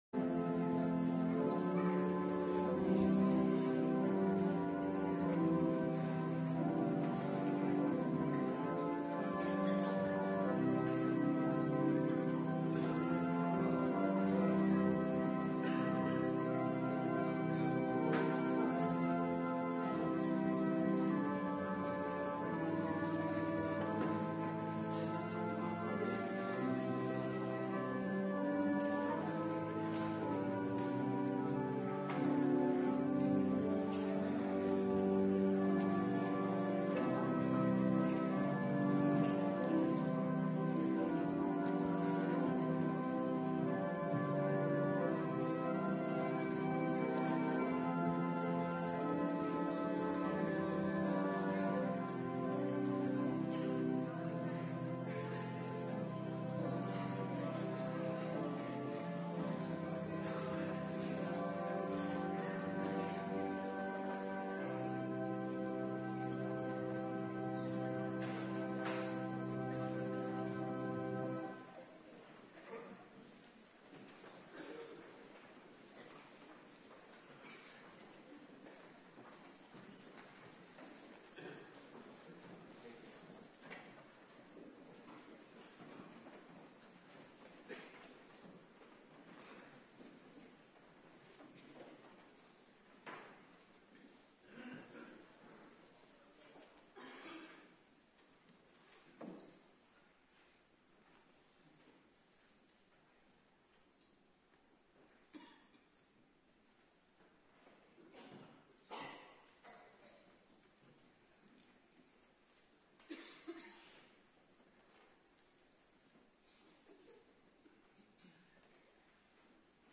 Morgendienst
09:30 t/m 11:00 Locatie: Hervormde Gemeente Waarder Agenda: Kerkdiensten Terugluisteren Mattheus 6:25-34